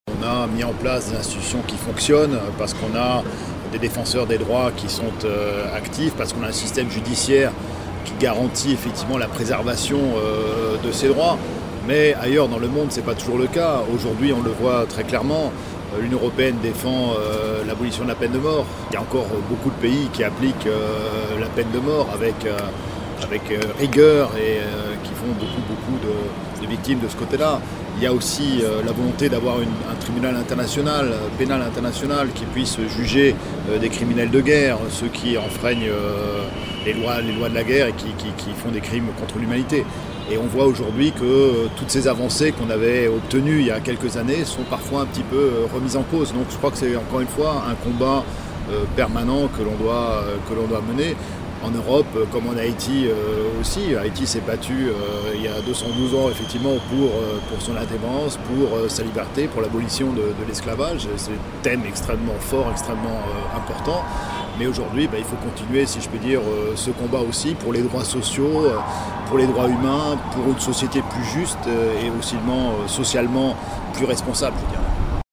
Deklarasyon reprezantan Inyon Ewopeyèn nan ann Ayiti, Vincent Degert